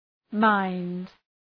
Προφορά
{maınd}